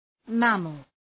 Προφορά
{‘mæməl}